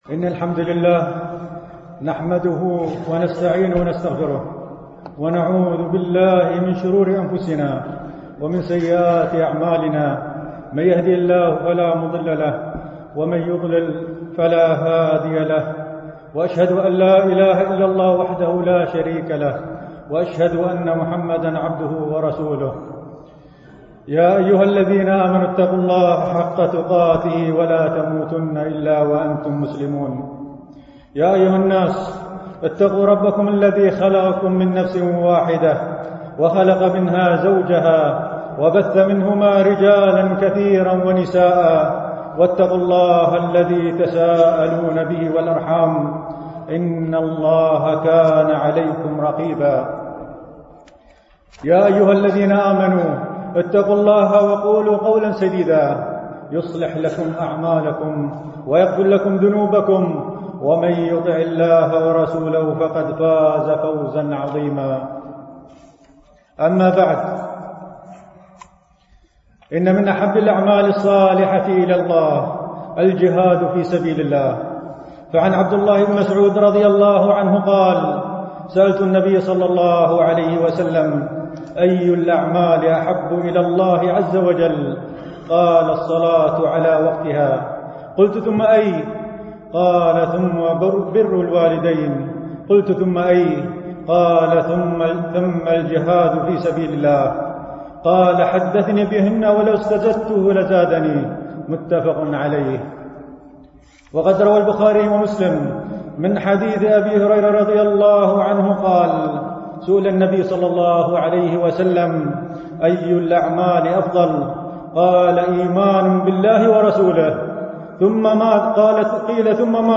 دروس مسجد عائشة (برعاية مركز رياض الصالحين ـ بدبي)
MP3 Mono 22kHz 32Kbps (CBR)